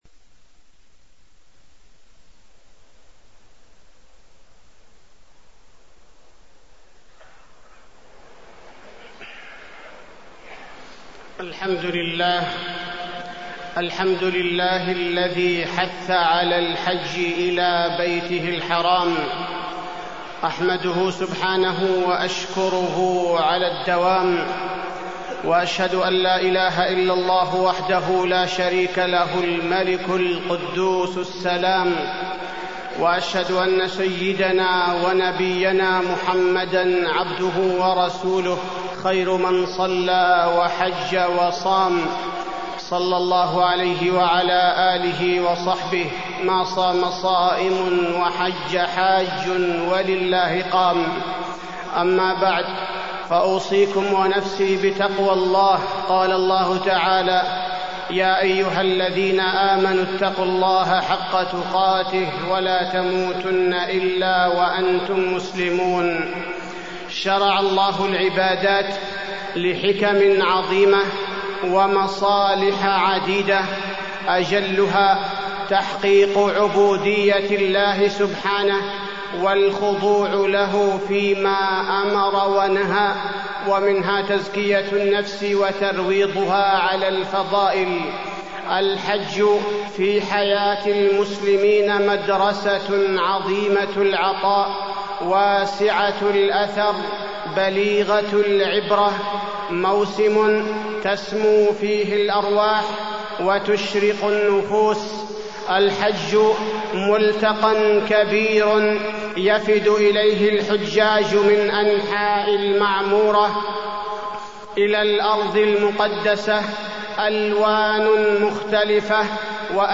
تاريخ النشر ٢٤ ذو القعدة ١٤٢٤ هـ المكان: المسجد النبوي الشيخ: فضيلة الشيخ عبدالباري الثبيتي فضيلة الشيخ عبدالباري الثبيتي الحج